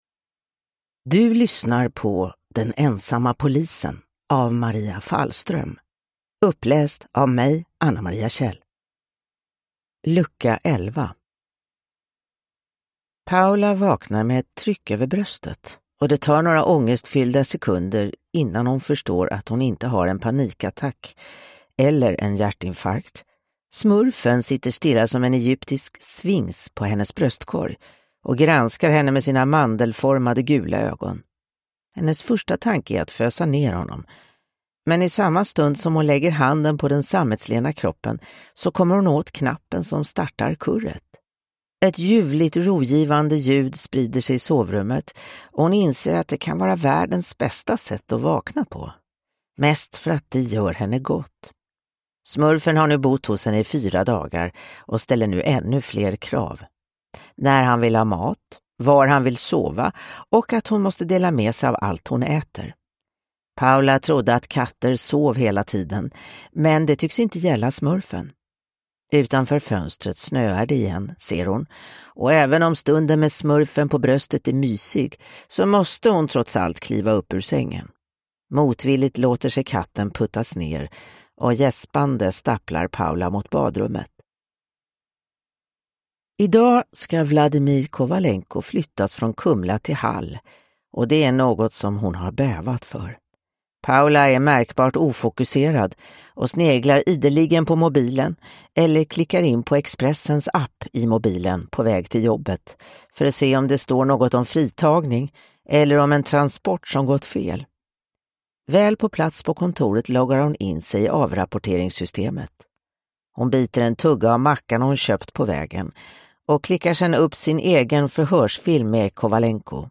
Den ensamma polisen: Lucka 11 – Ljudbok